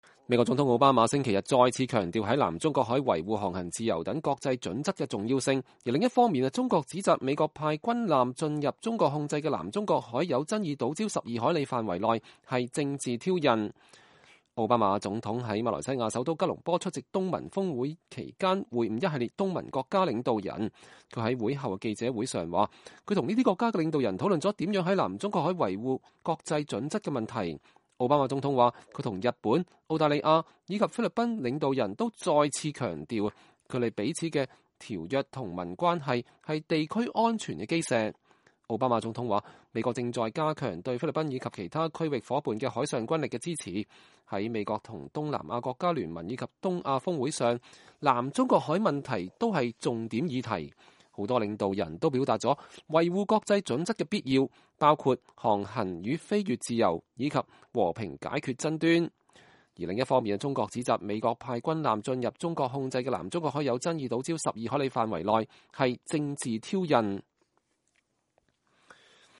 奧巴馬在吉隆坡的記者會上